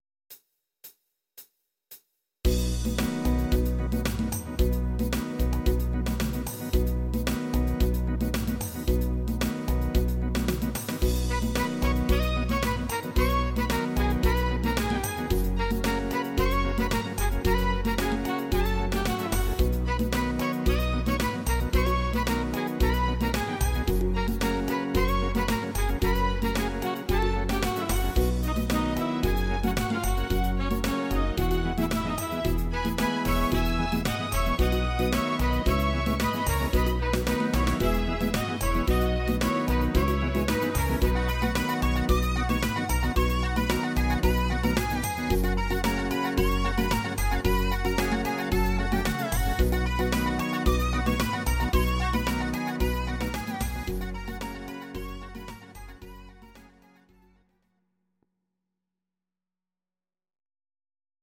These are MP3 versions of our MIDI file catalogue.
Please note: no vocals and no karaoke included.
instr. Orchester